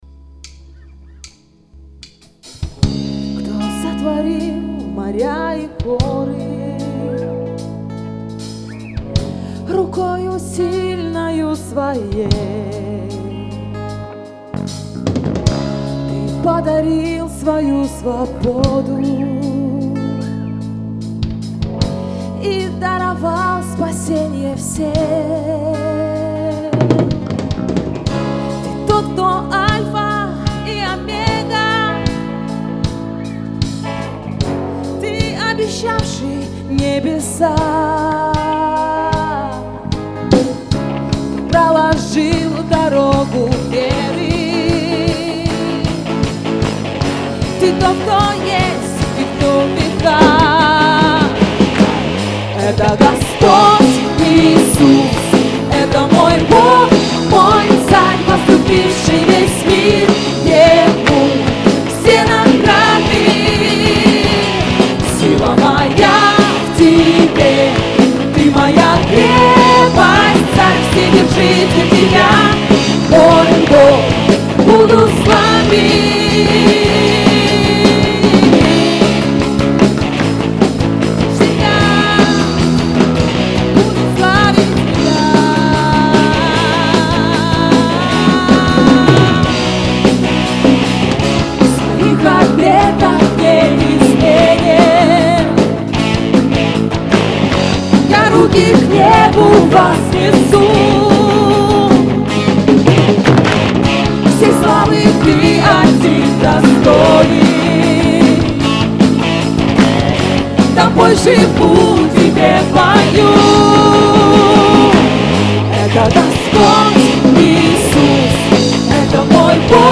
11437 просмотров 4052 прослушивания 366 скачиваний BPM: 150